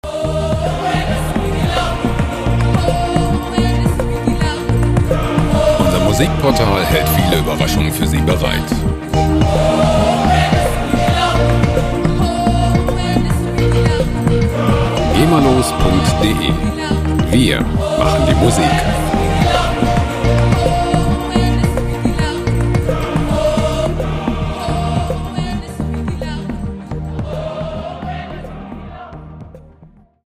free World Music Loops
Musikstil: World Music
Tempo: 108 bpm